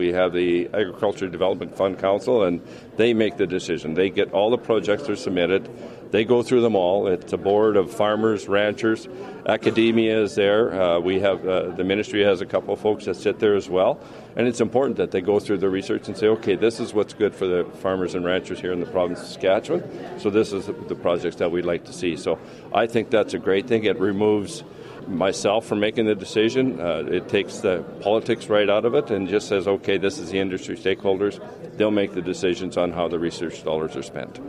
Agriculture Minister David Marit makes the annual announcement for grains at the Western Canadian Crop Production Show in Saskatoon.